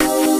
neptunesambient4.wav